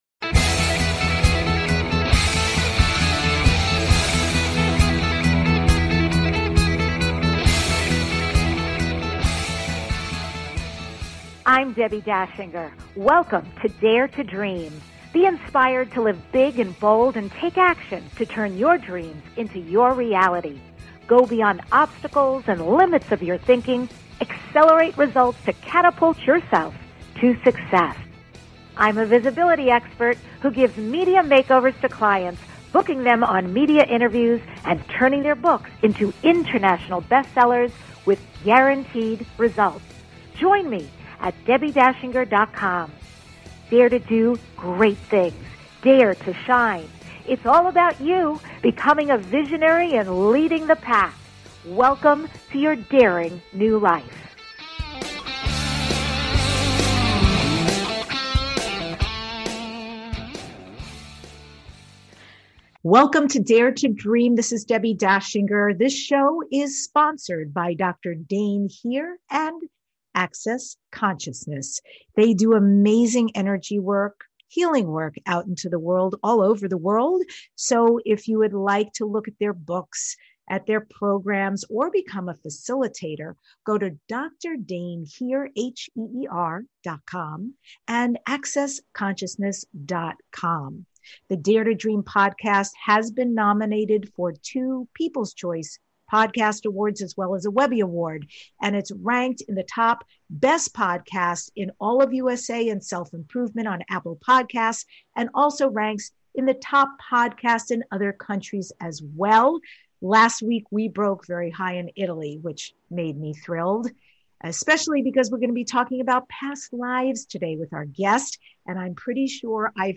The award-winning DARE TO DREAM Podcast is your #1 transformation conversation.